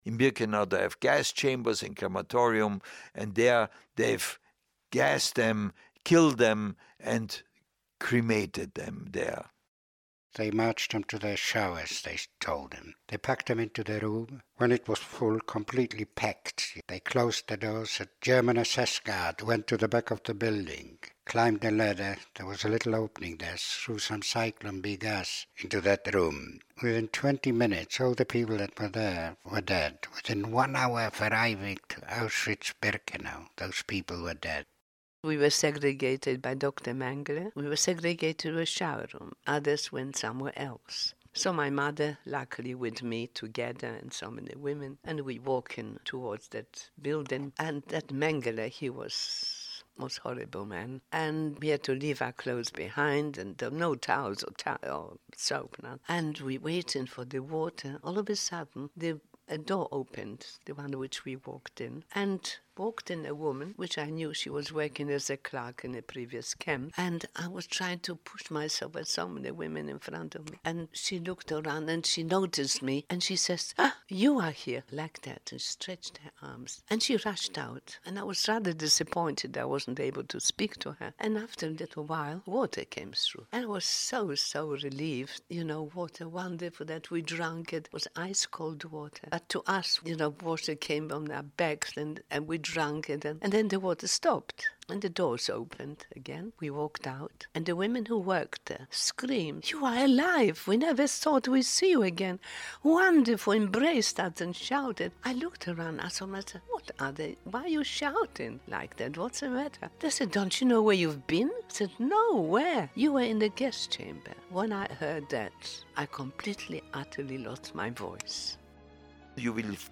Never forget the tragedies that made the Jewish population loose 6million citizens. Here are three survivors telling their traumatic experiences